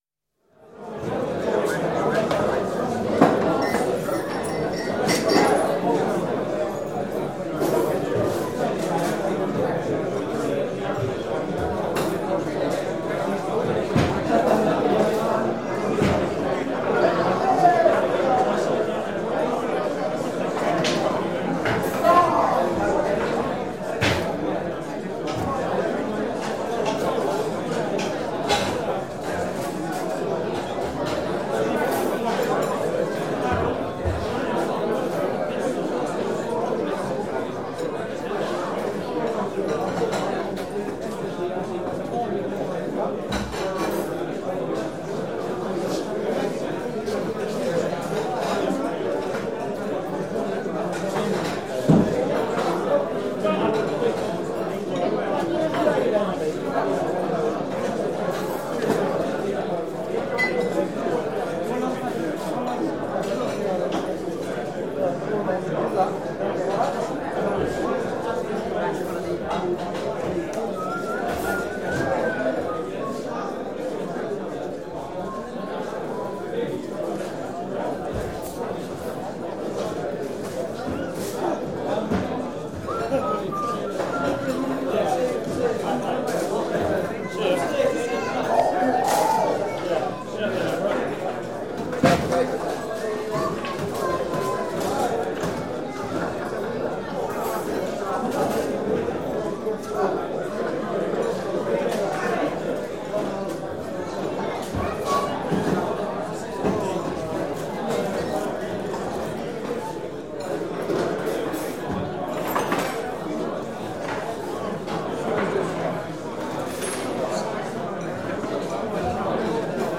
bbc_rewind_crowds.mp3